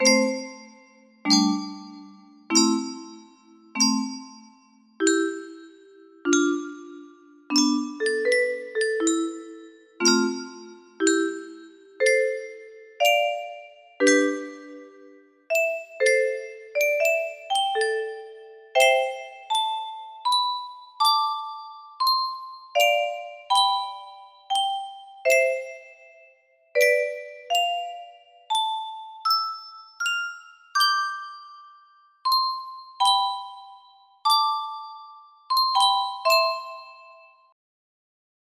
Hold music box melody
Full range 60